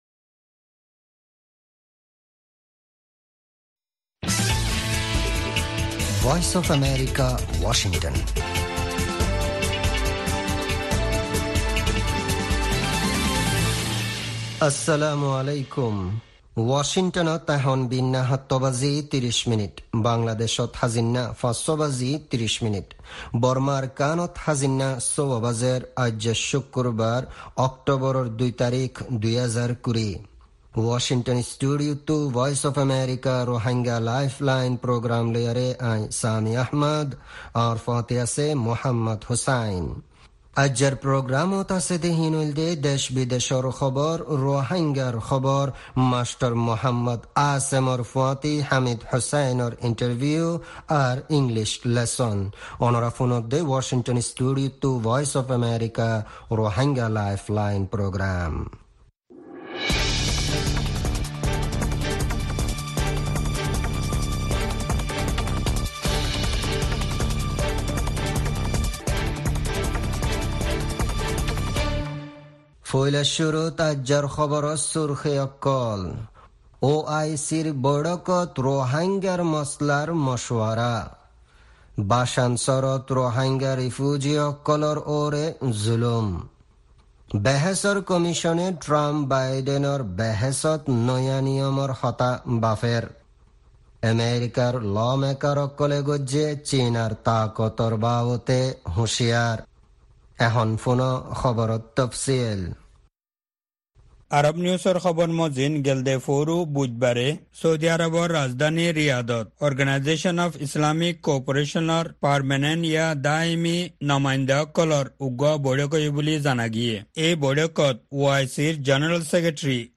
Embed Rohingya Broadcast Embed The code has been copied to your clipboard.